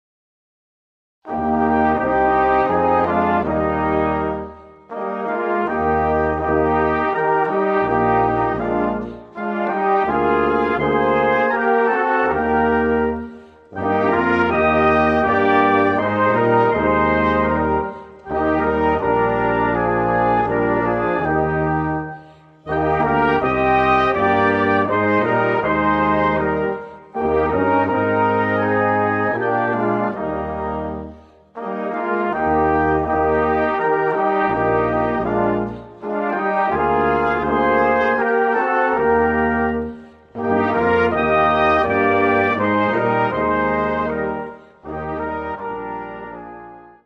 Komponist: Volksweise
Gattung: Vom Quartett zum Orchester
Besetzung: Ensemble gemischt